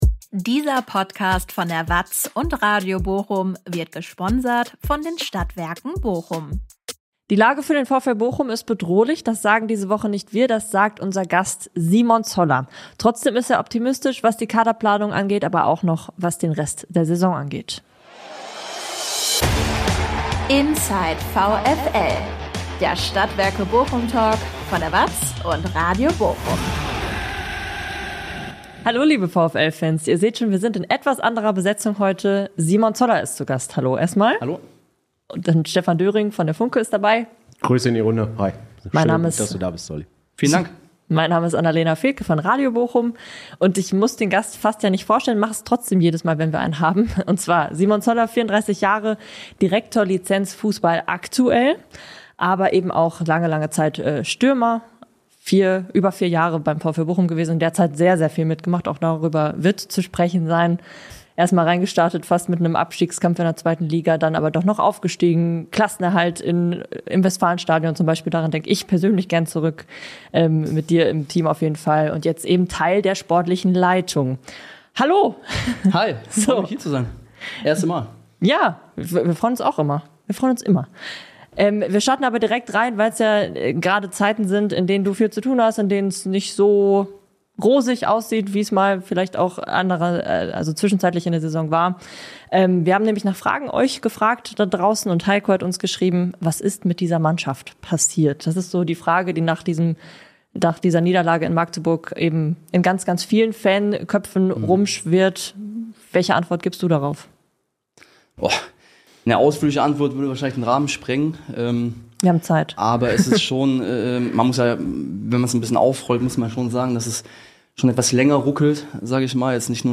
Nach der 1:4-Niederlage beim 1. Magdeburg verschärft sich die Lage beim VfL Bochum. In einer Spezial-Folge des VfL-Talks spricht Simon Zoller, Direktor Lizenzfußball, über die Situation und die Kaderplanung.